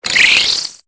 Cri de Tic dans Pokémon Épée et Bouclier.